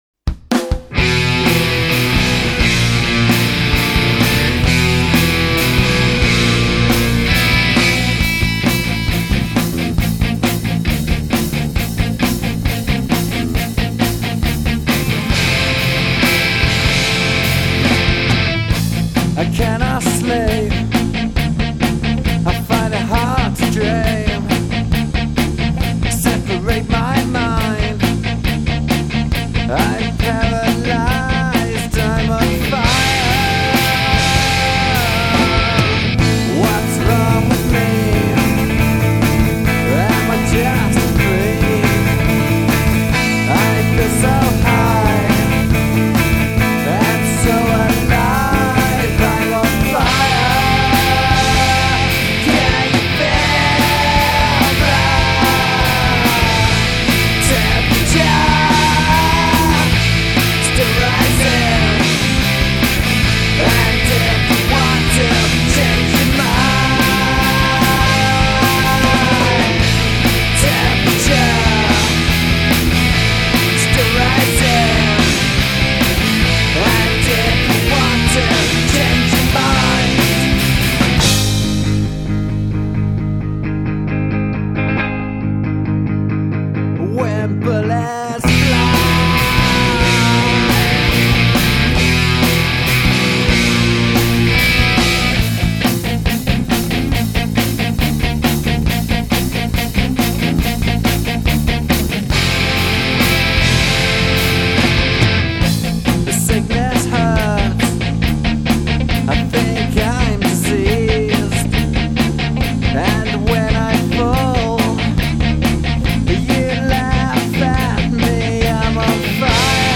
80s metal riff tune well cool
Heavy metal
Experimental
Nu-metal